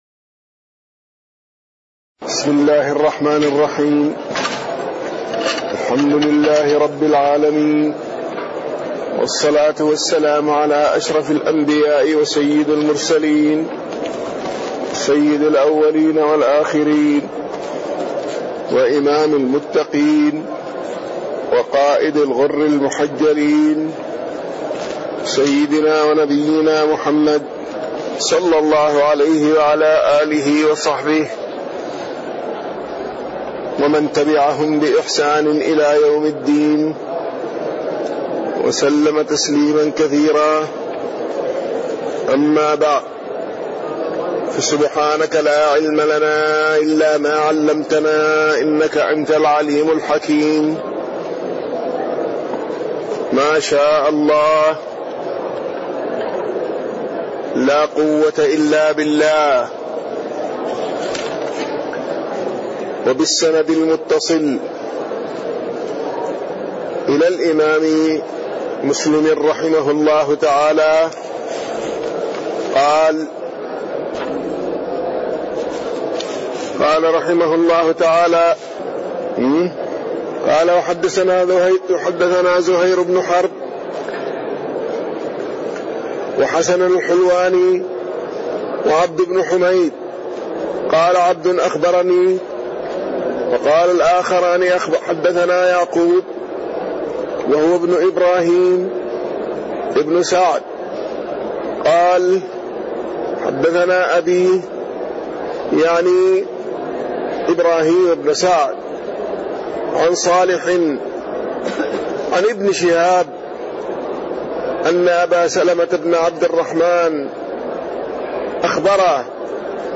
تاريخ النشر ١٩ ربيع الأول ١٤٣٢ هـ المكان: المسجد النبوي الشيخ